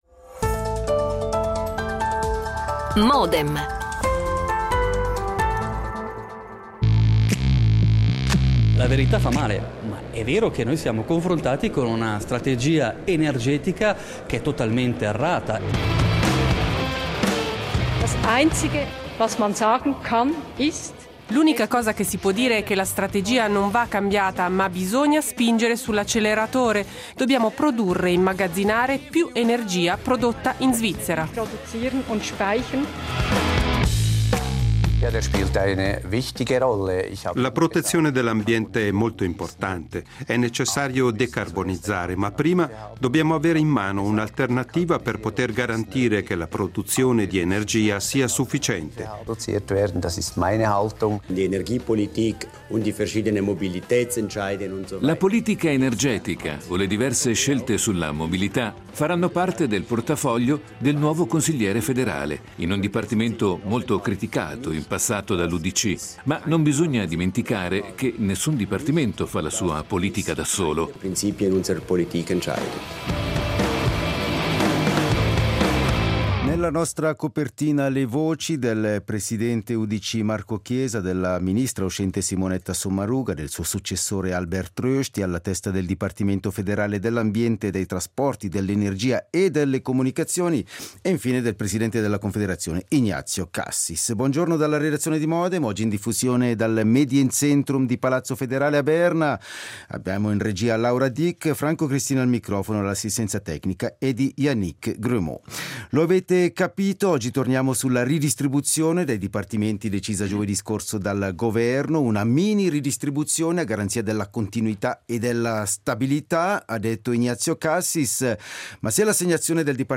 Ne discutiamo con quattro consiglieri nazionali, in rappresentanza dei quattro partiti di governo:
L'attualità approfondita, in diretta, tutte le mattine, da lunedì a venerdì